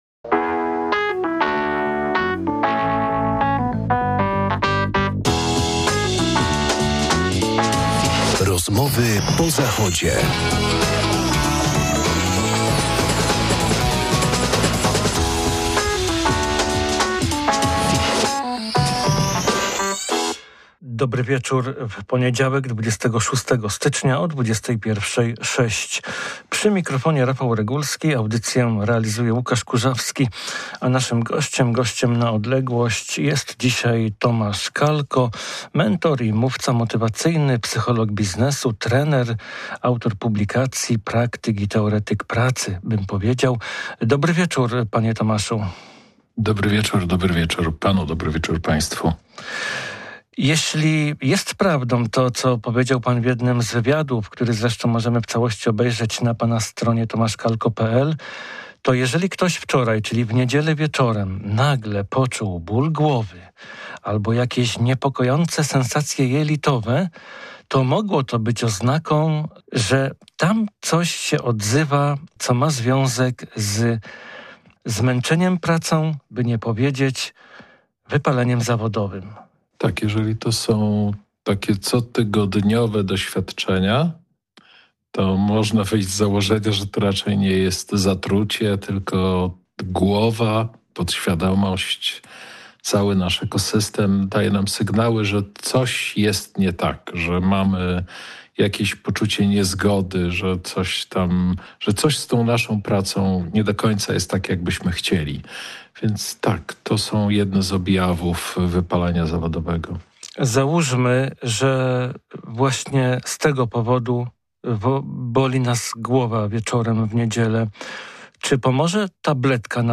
Od wypalenia zawodowego przez work-life balance do work life-integration: o tym jest audycja z udziałem mentora i trenera, psychologa biznesu